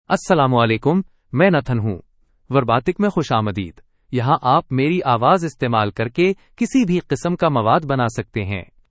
MaleUrdu (India)
Nathan is a male AI voice for Urdu (India).
Voice sample
Listen to Nathan's male Urdu voice.
Nathan delivers clear pronunciation with authentic India Urdu intonation, making your content sound professionally produced.